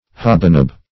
Hobanob \Hob"a*nob`\, Hobandnob \Hob"and*nob`\, v. i.